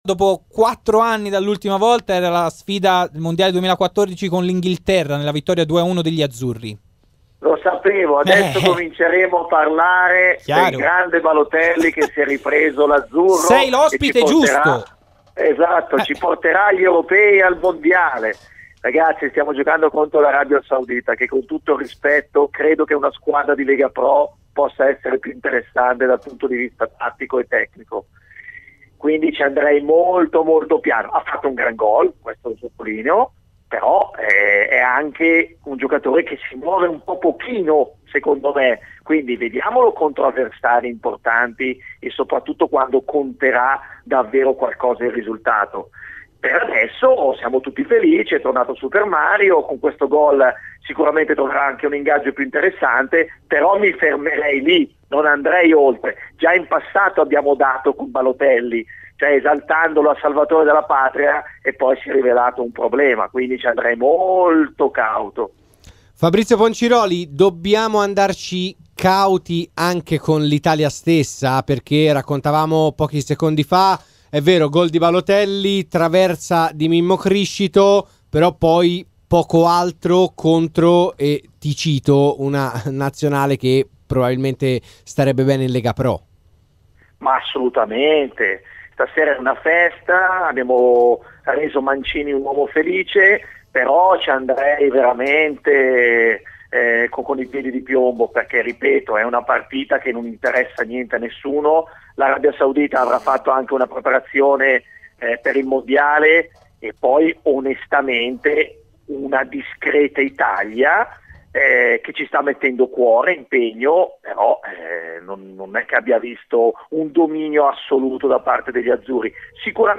Intervenuto nel corso del Live Show di RMC Sport
In studio